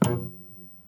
crt_startup.mp3